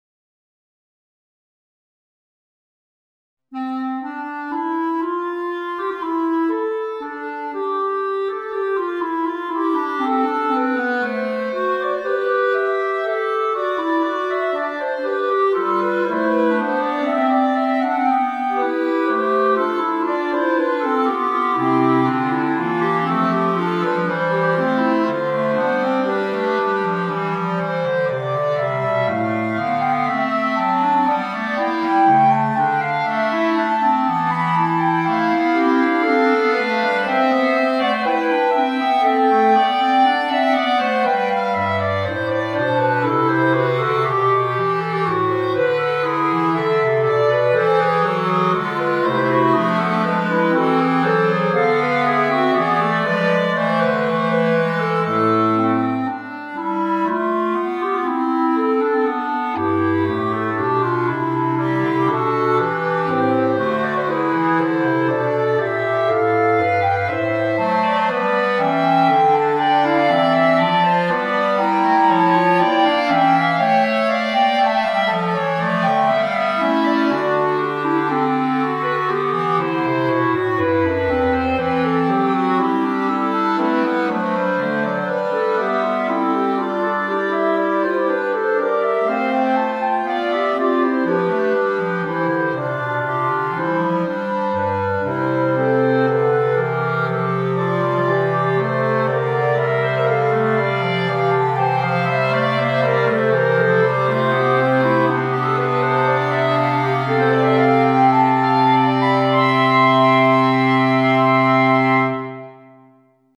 per quartetto di clarinetti